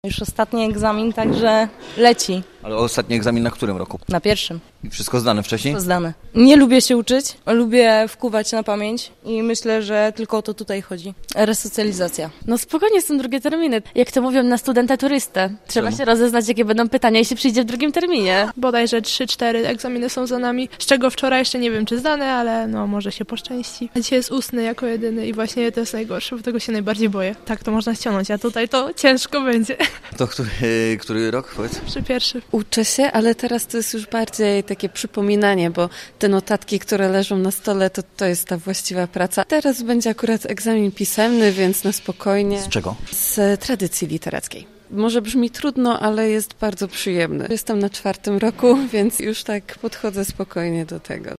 Radio Zielona Góra w Polowym Studiu odwiedziło dzisiaj żaków na campusie B przy al. Wojska Polskiego: